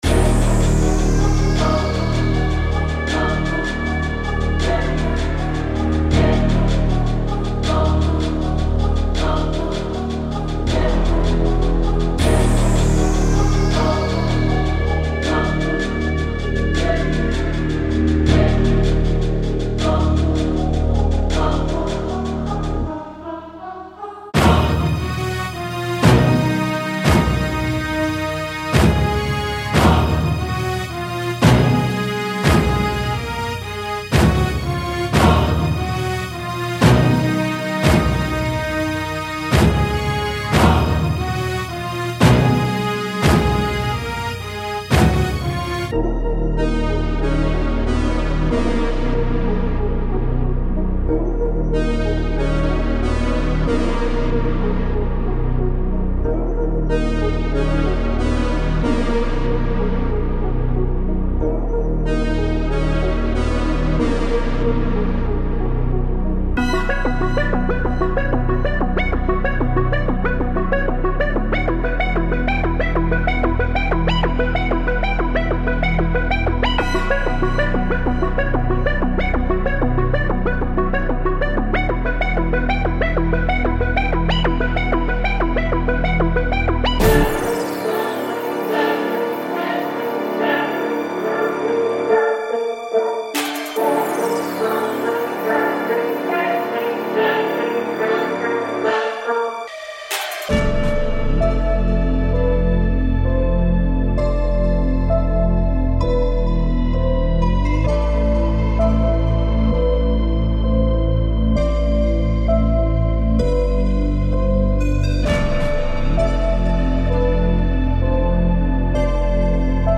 此系列中包括八种原始作品，充满了深情的钢琴，合唱团，坚硬的贝斯，现代合成器，铜管乐器等等，并且都带有独立的音轨。
演示中的所有声音都包含在此产品中。